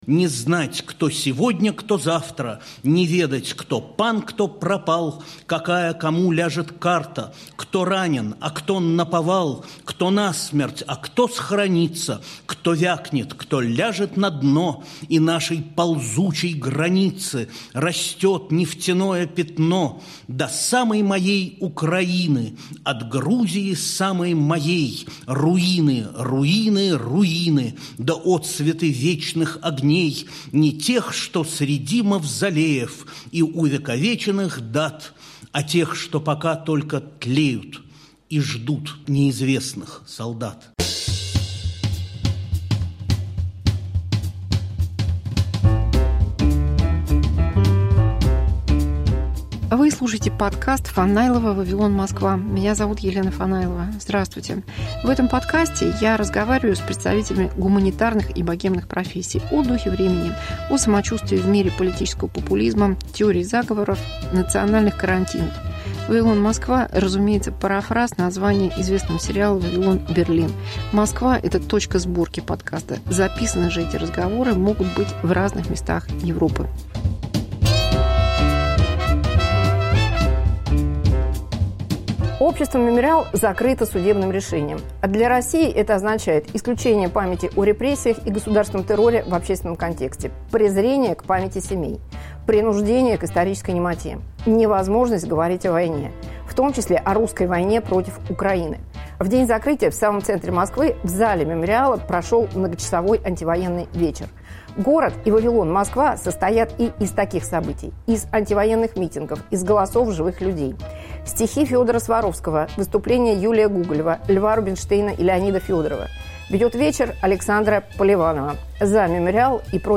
Поэты, актеры и музыканты на антивоенном вечере в "Мемориале". Часть 1